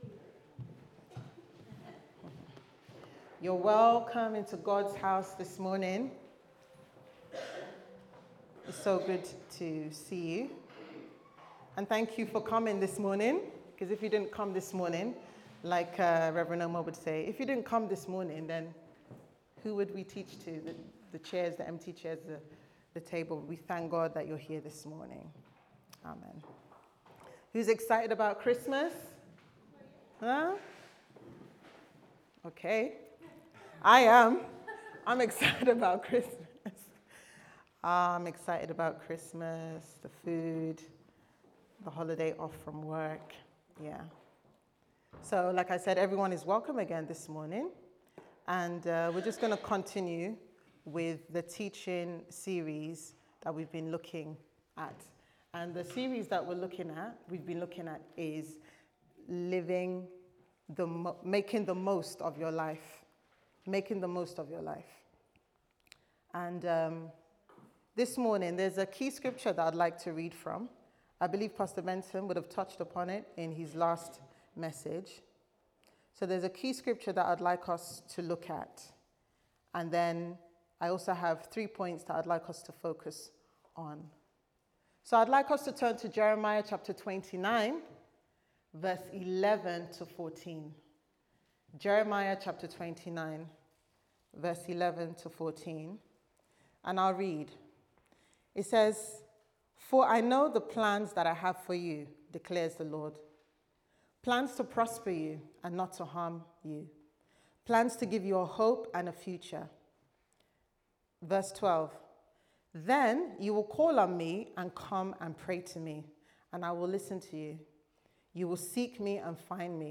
Making The Most Out of Life Service Type: Sunday Service Sermon « Making The Best Of Your Life